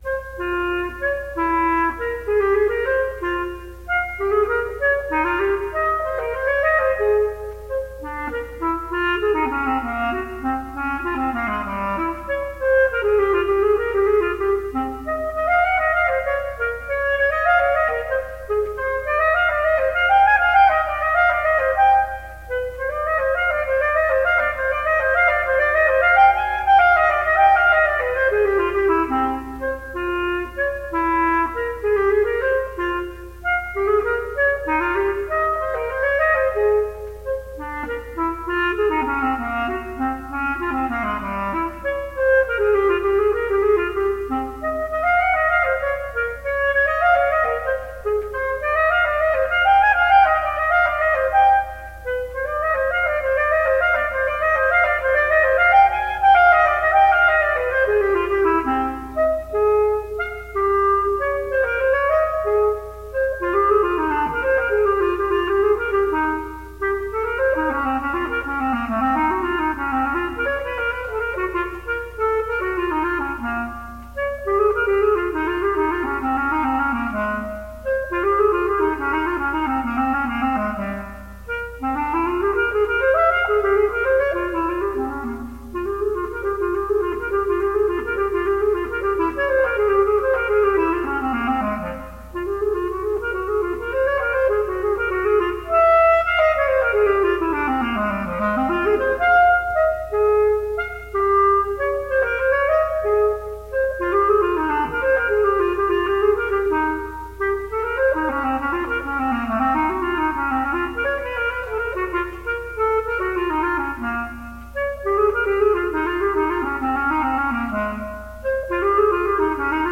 Classical solo clarinet.